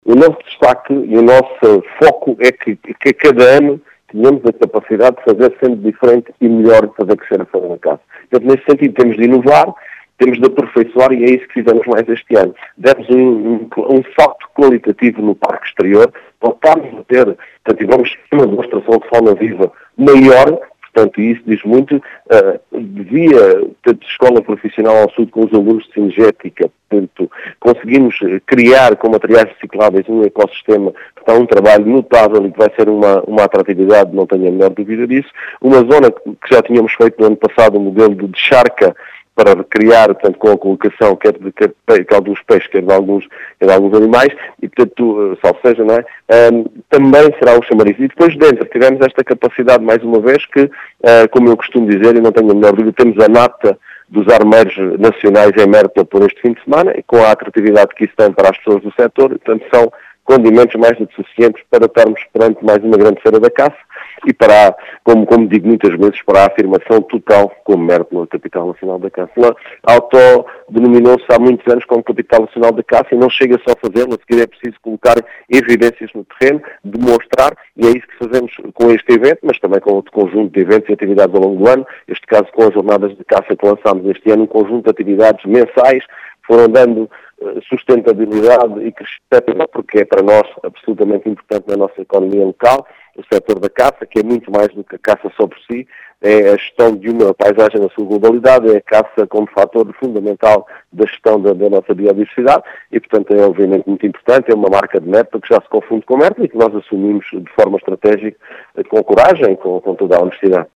As novidades e os destaques foram deixados, por Mário Tomé, presidente da Câmara Municipal de Mértola, que realça a importância do sector na economia local.